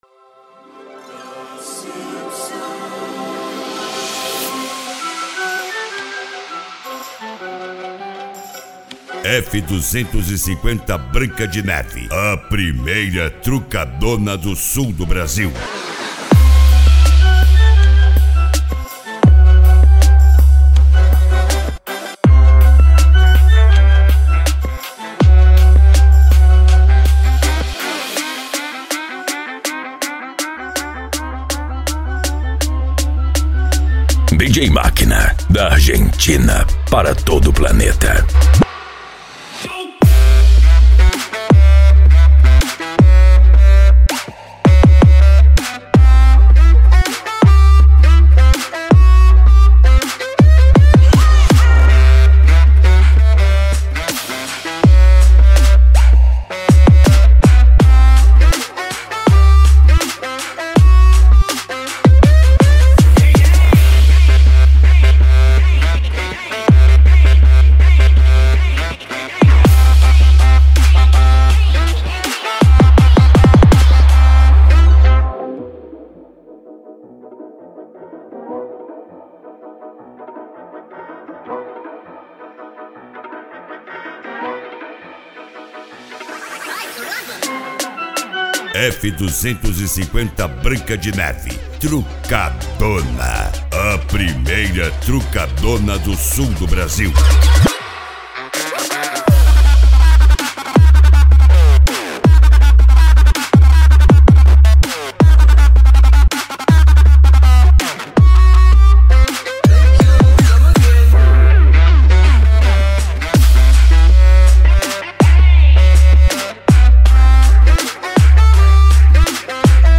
Funk Nejo
Hard Style
PANCADÃO